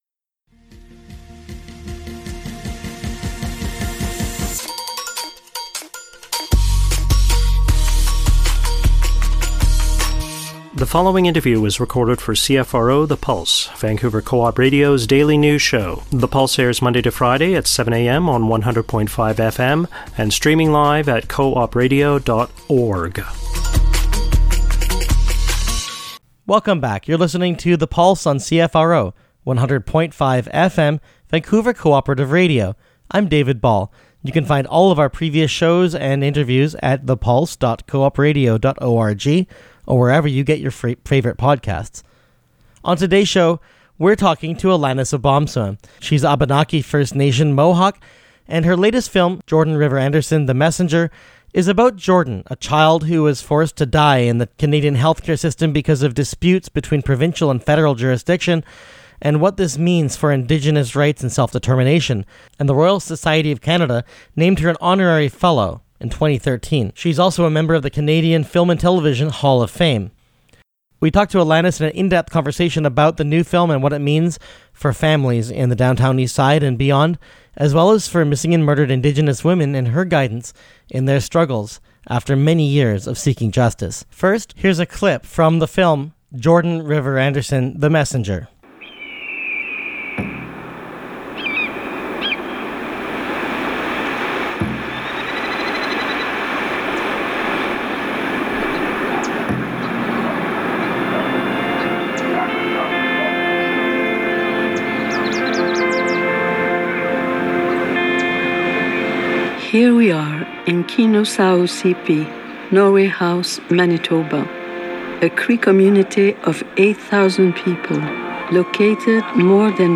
pulse_interview_aug_25_podcast.mp3